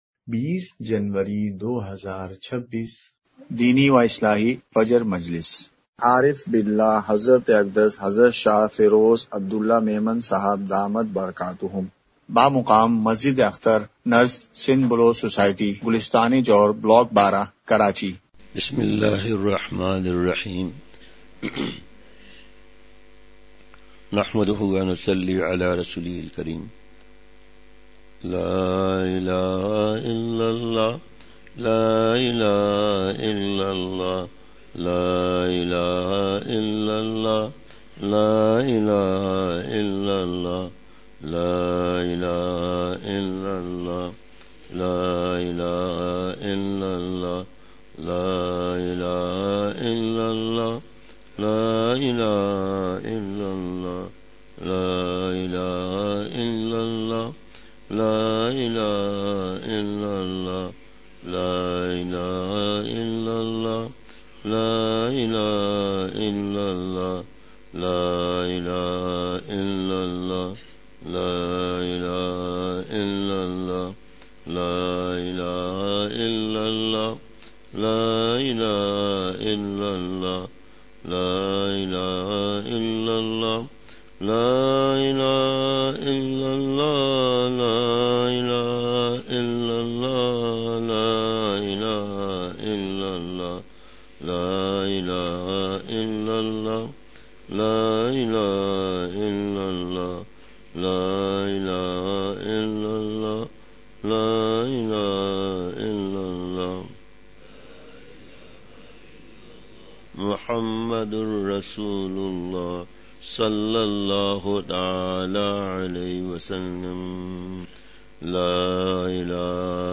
مقام:مسجد اختر نزد سندھ بلوچ سوسائٹی گلستانِ جوہر کراچی*